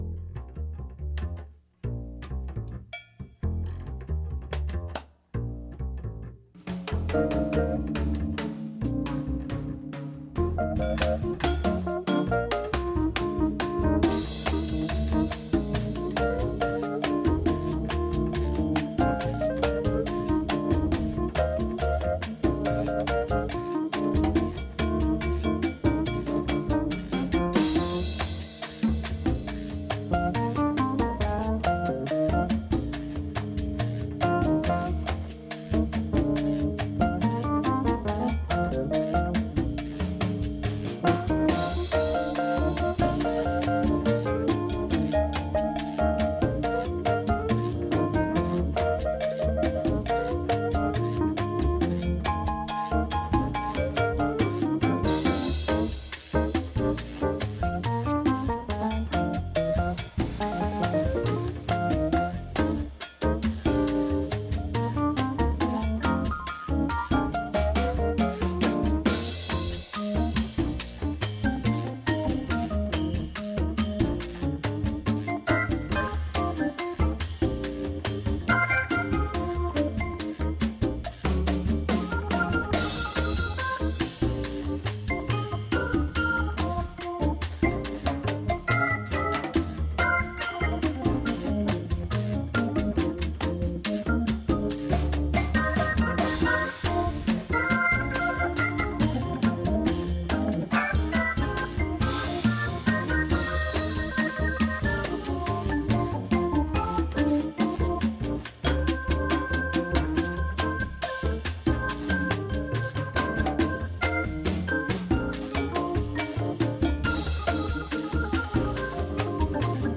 επιλογές από ό,τι μένει από τα όμορφα ακούσματα της εβδομάδας σε ένα χαλαρό “απογευματινό καφέ”.